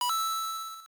DonationSound.mp3